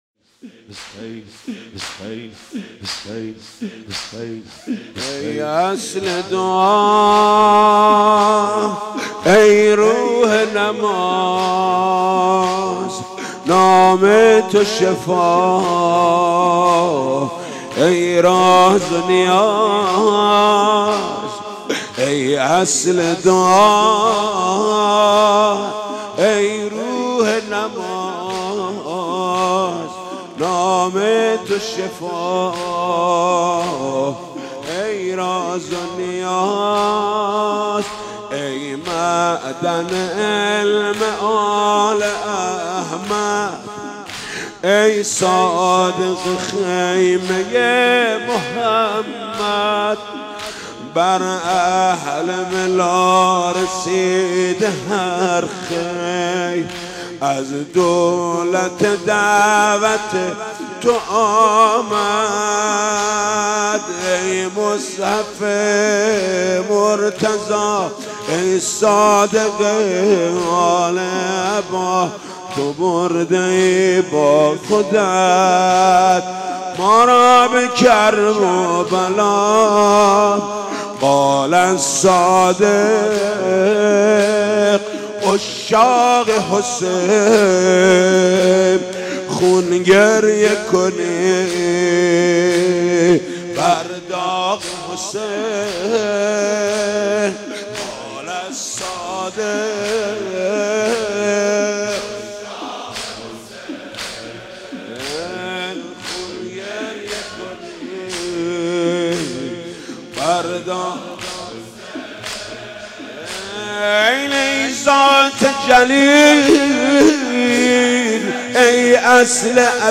«شهادت امام صادق 1396» زمینه: ای اصل دعا ای روح نماز
«شهادت امام صادق 1396» زمینه: ای اصل دعا ای روح نماز خطیب: حاج محمود کریمی مدت زمان: 00:06:57